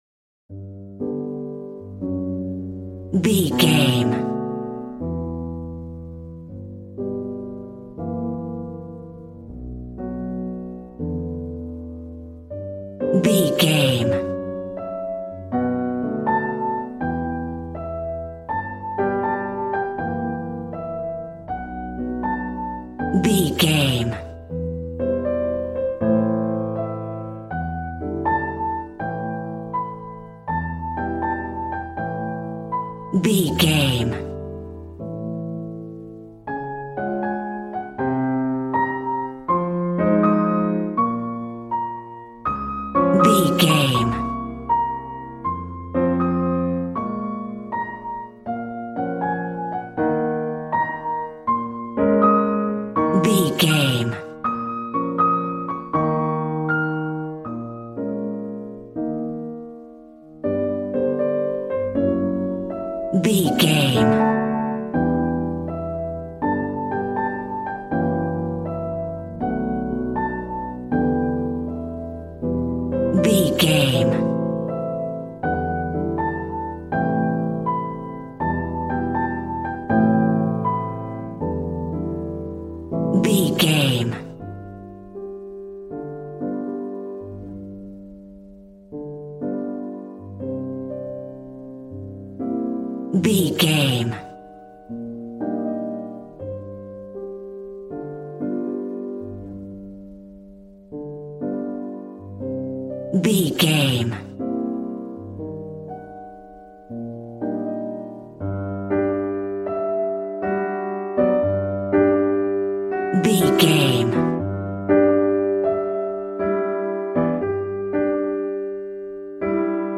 Aeolian/Minor
smooth
piano
drums